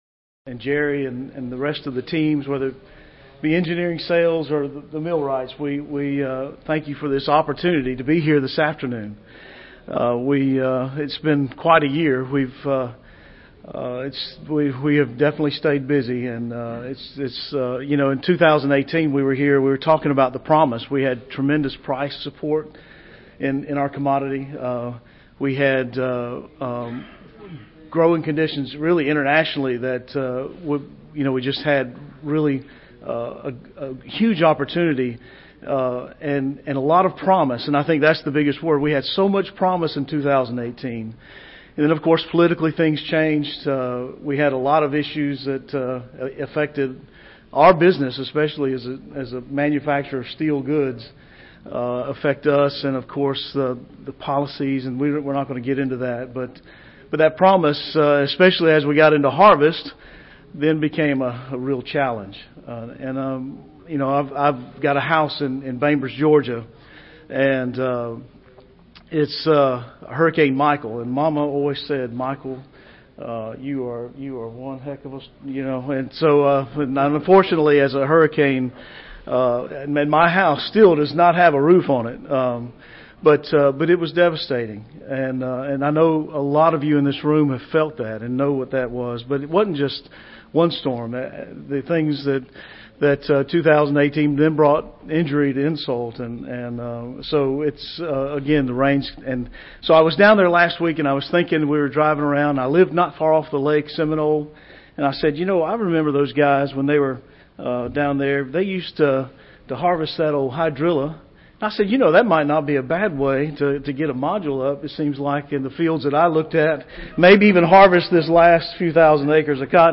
Cherokee Fabrication Company Inc. Audio File Recorded Presentation An exploration into how developing technologies have improved the manufacture and control of the modern gin plant while looking forward to the challenges of tomorrow.